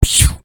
hard_drop.ogg